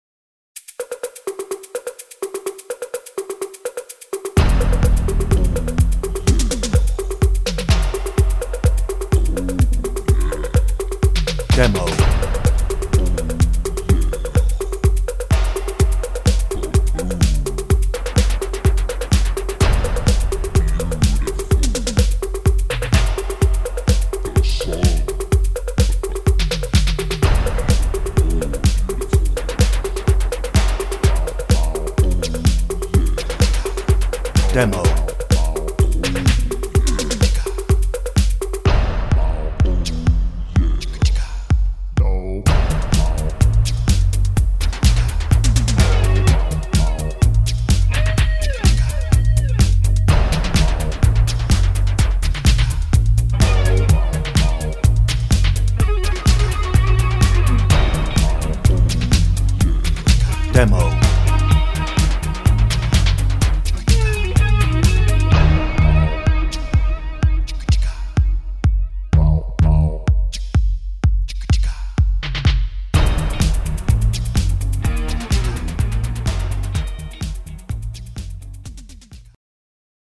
Hoedown - No ref vocal
Instrumental